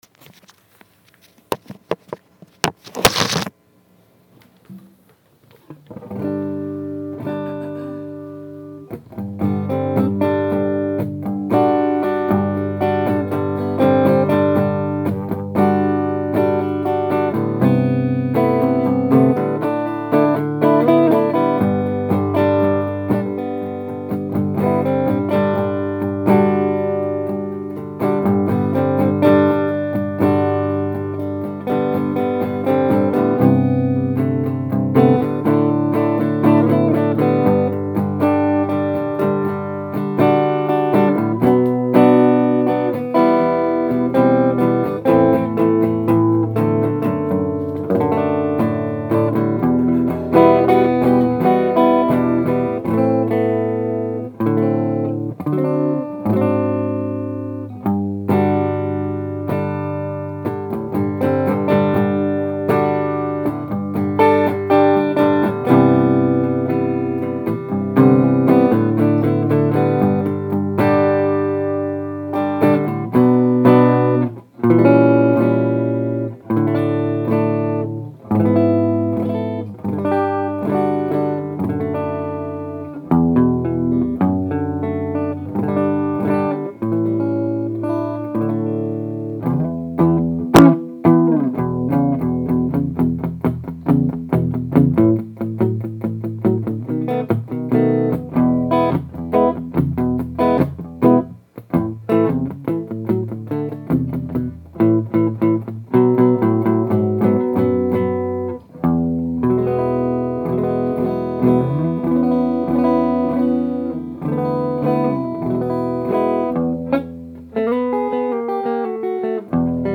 And here's a bit of recording, using my cell phone as a recorder and plugging the guitar into a Crate bass amp that I had handy. Note that the thunky deadness of the bass strings is a function of the flatwounds:
It's not a beautiful acoustic guitar sound, but I think it could work well as a rhythm guitar in a band.
it seems like it worked pretty well, and the sound hole cover will keep the feedback down. it makes your acoustic sound like a hollow-body electric. pretty cool. i think the sound hole covers are pretty cheap too. i put a pup on an acoustic bass i have and it came out like this. i modeled it after the dean markley type acoustic pup.